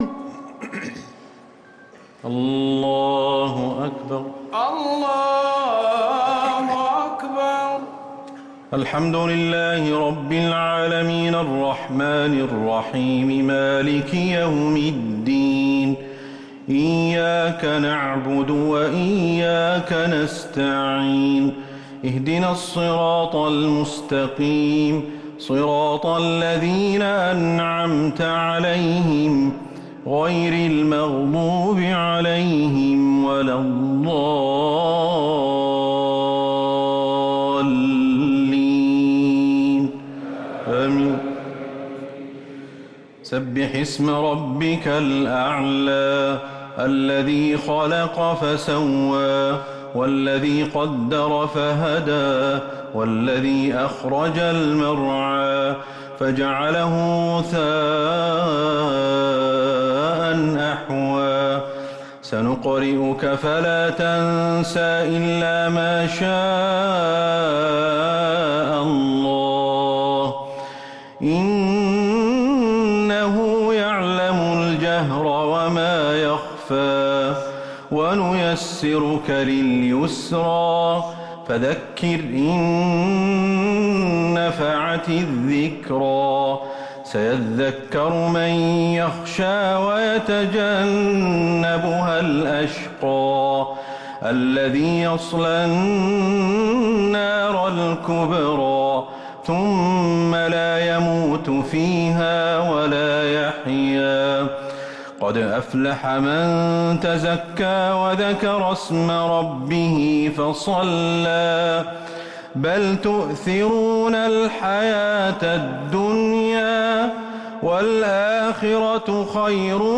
صلاة التراويح ليلة 13 رمضان 1444